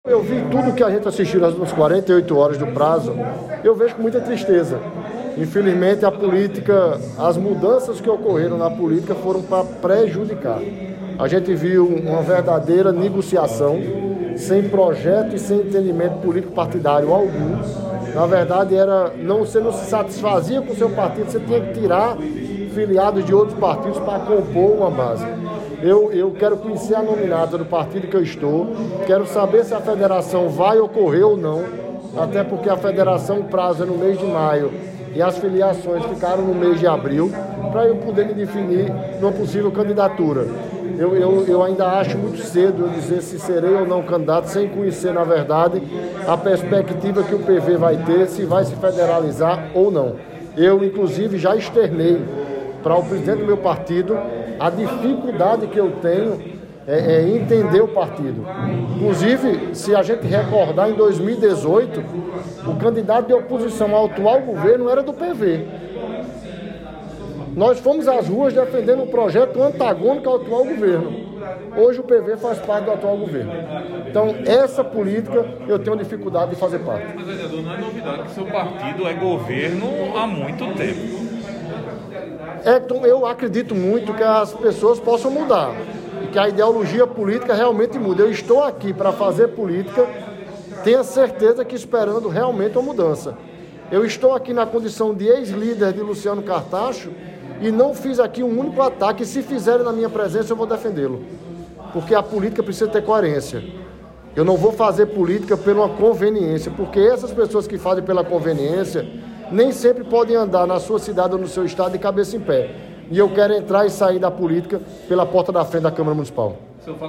A declaração do vereador Milanez foi a equipe de reportagem do Portal PautaPB.
Abaixo a fala do vereador Fernando Milanez.